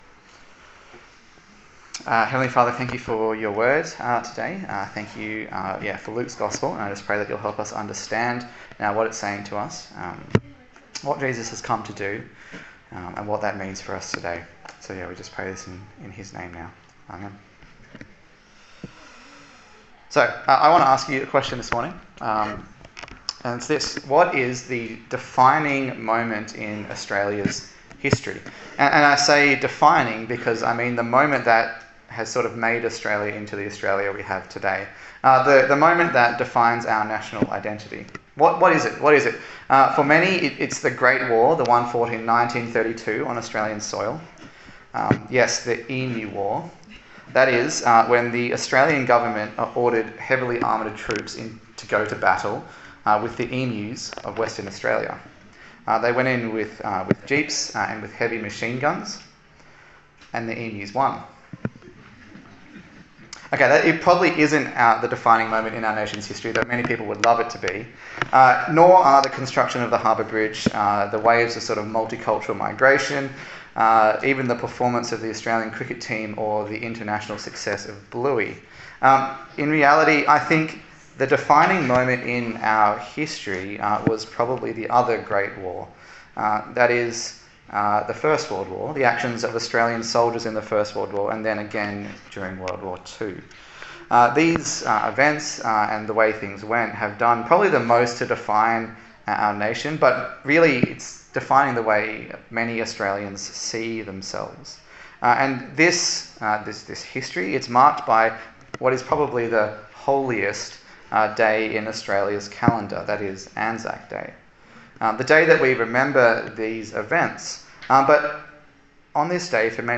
Luke Passage: Luke 22:1-38 Service Type: Sunday Service